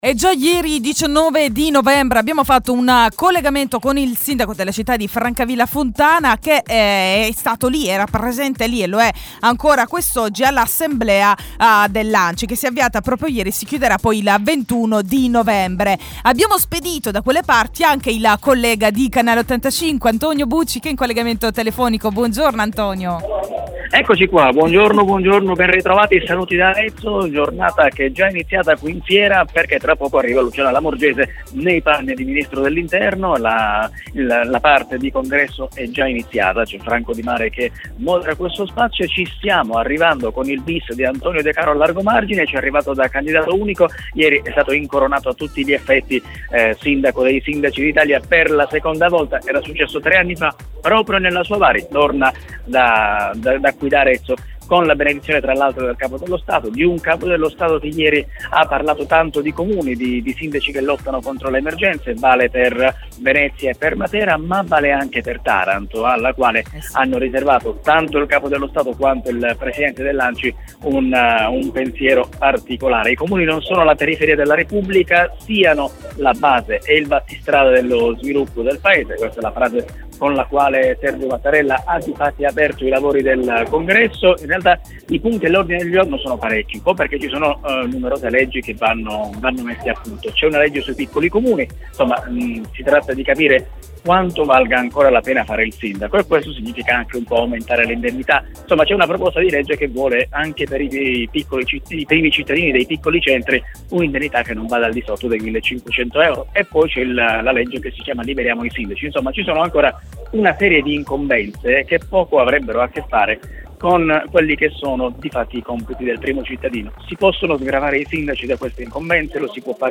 Il Mattino di Radio85 – Assemblea ANCI dal 19 al 21 novembre ad Arezzo. In collegamento telefonico il giornalista